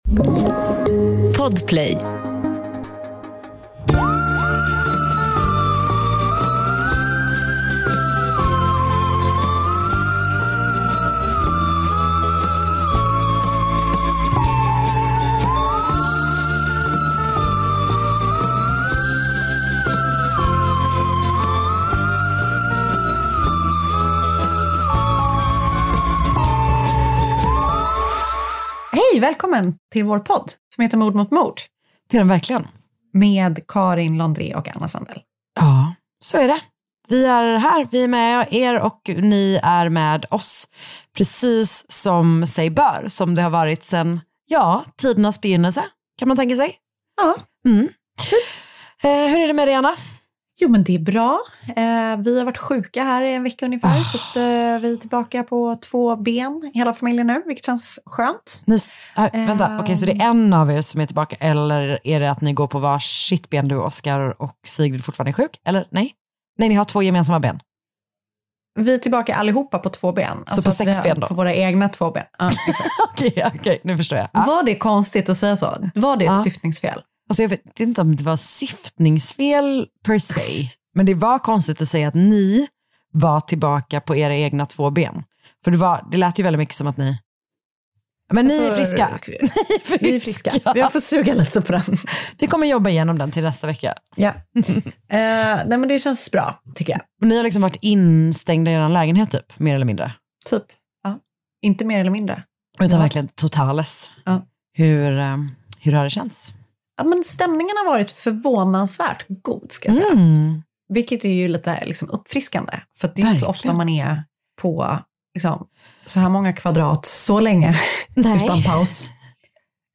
Mord Mot Mord är en vanlig snackig podd, fast om mord. Det är lättsamt prat i ett försök att hantera världens värsta ämne.